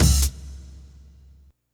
kick-hat.wav